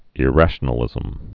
(ĭ-răshə-nə-lĭzəm)